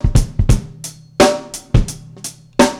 Badurim 3 86bpm.wav